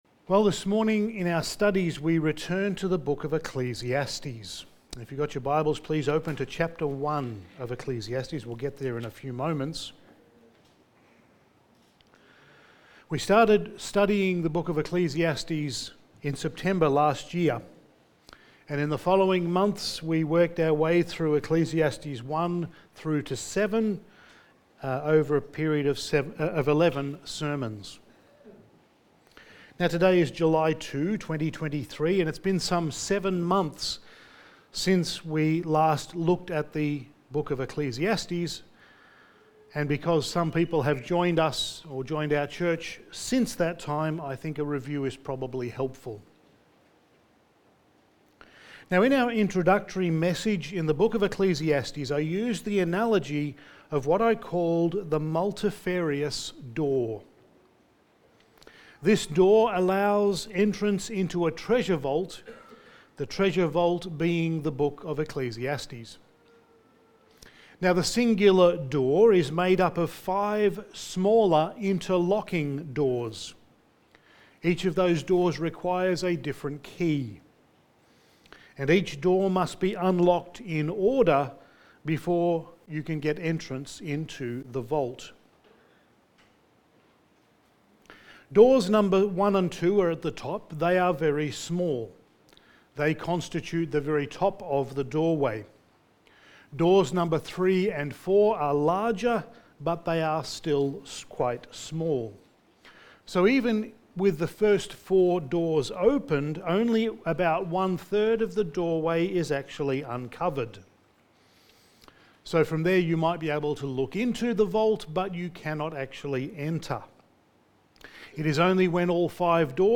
Studies in the Book of Ecclesiastes Sermon 12: ‘Better Than…’ Part 2
Service Type: Sunday Morning